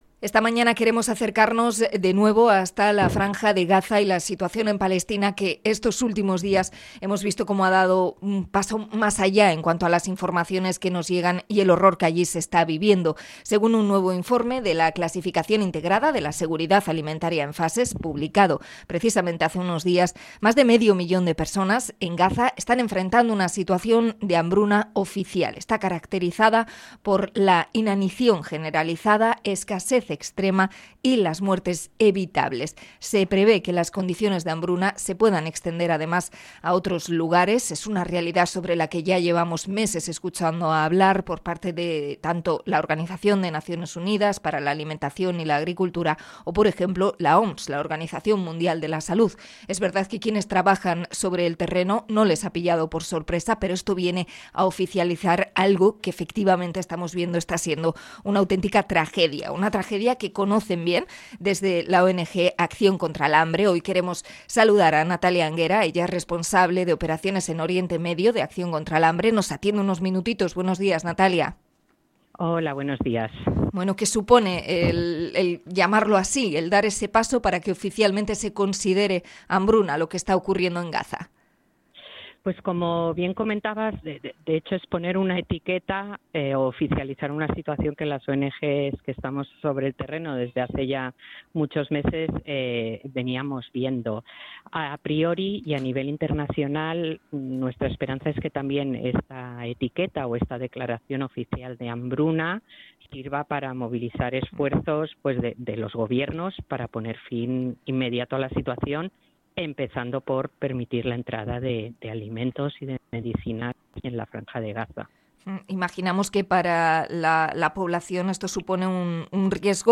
INT.-ACCION-CONTRA-EL-HAMBRE-GAZA.mp3